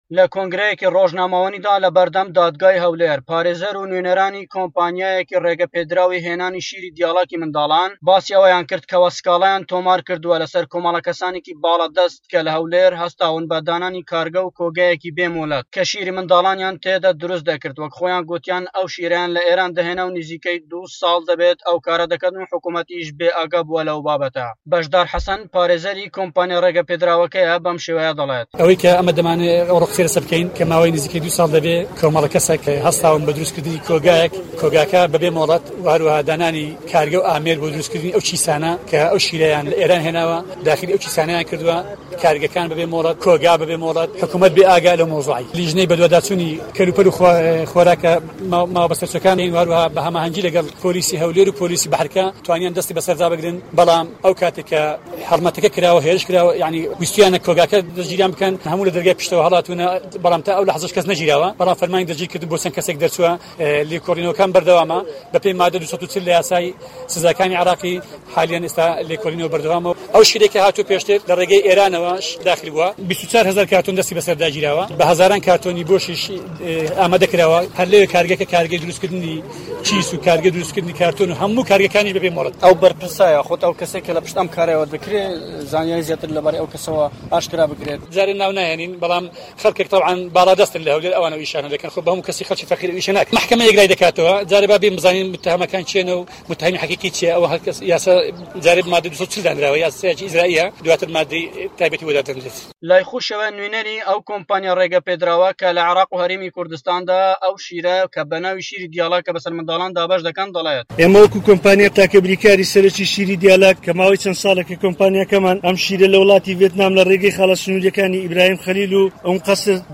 راپۆرتی